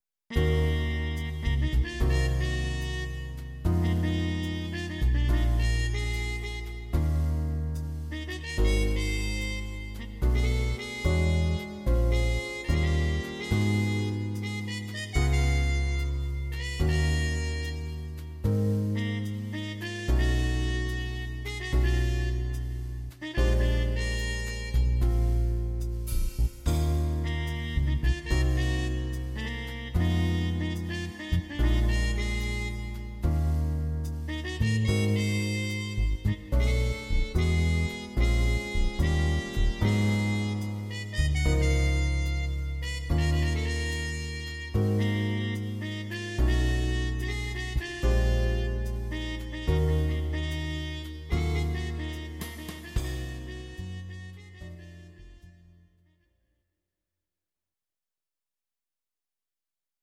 for Jazz quartet